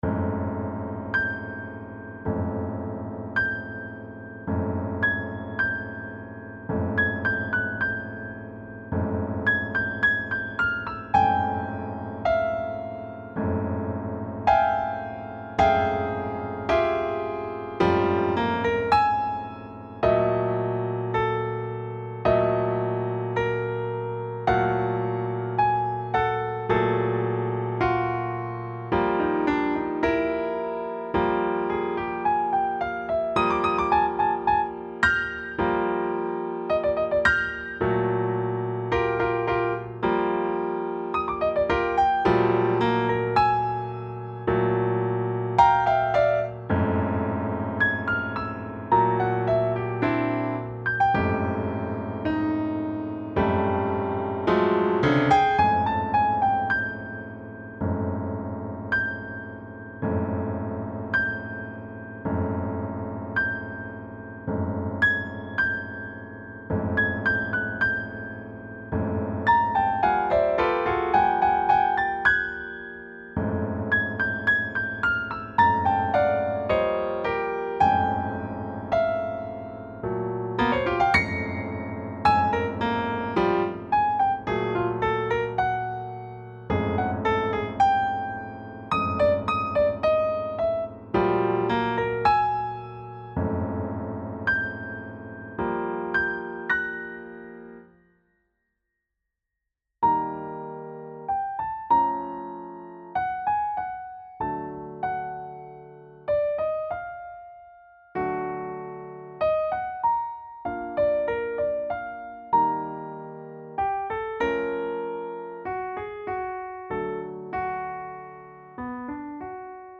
It drives me insane - Piano Music, Solo Keyboard - Young Composers Music Forum